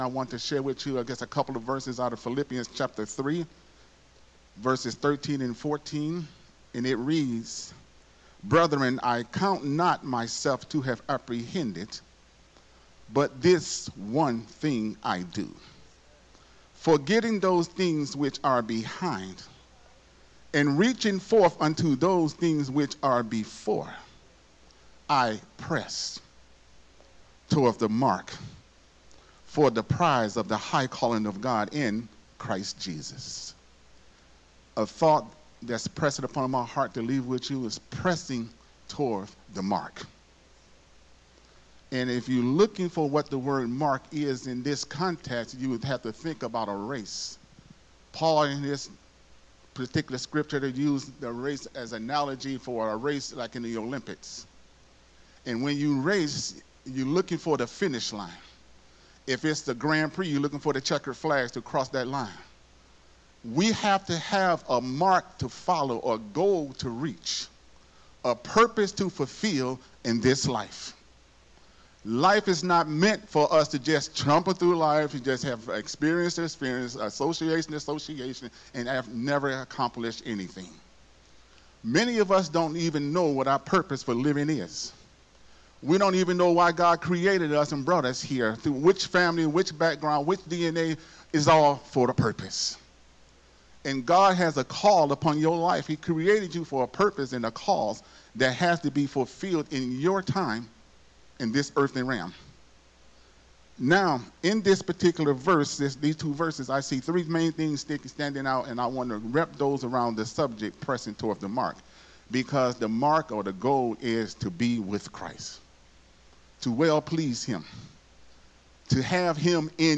Sunday Morning Message
Sunday Morning Worship Service Growth Temple Ministries